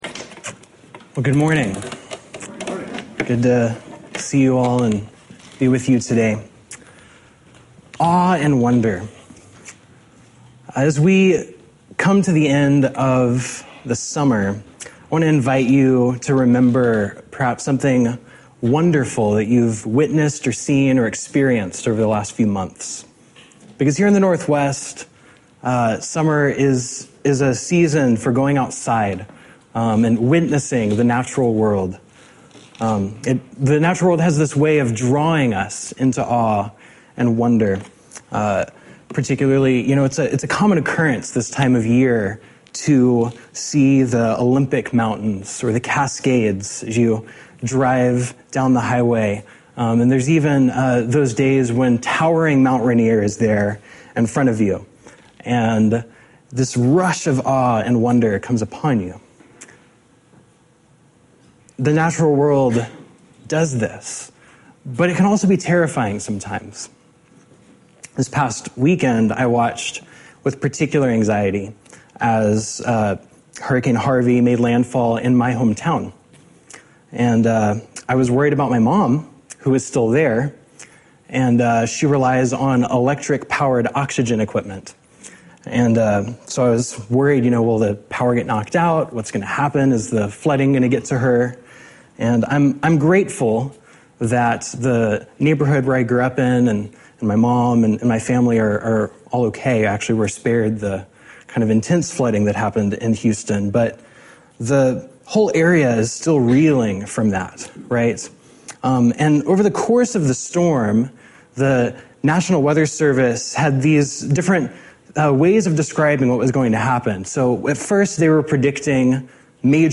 In the summer of 2017 I spoke at Sanctuary Church from a text of my choosing in the psalms or prophets.